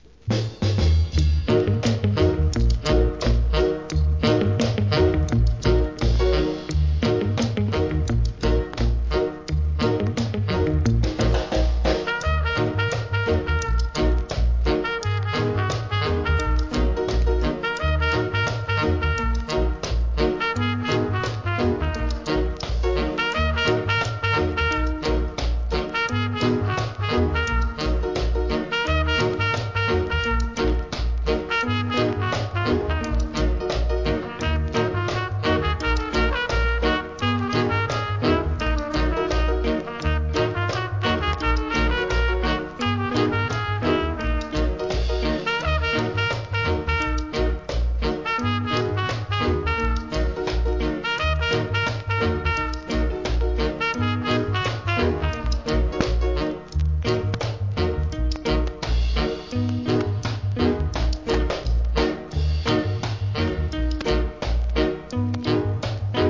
1. REGGAE
KILLER ROCKSTEADYのINST物!!!